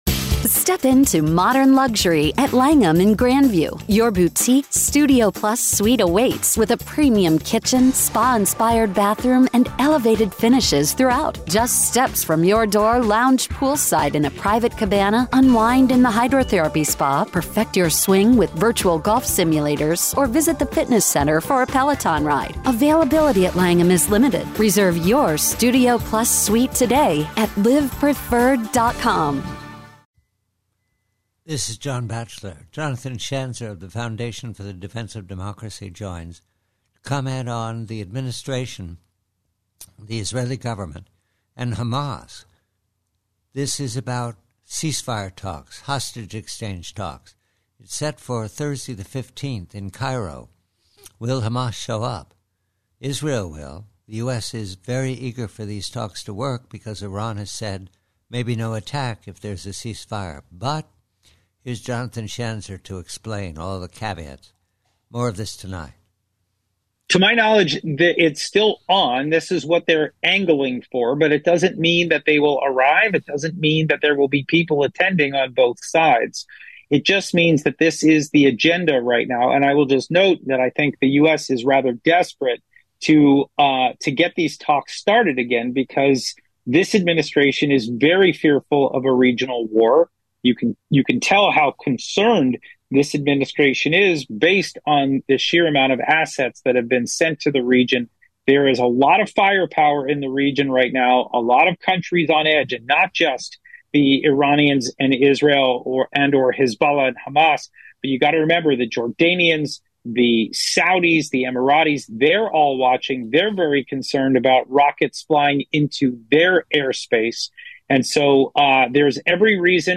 PREVIEW: HAMAS: Conversation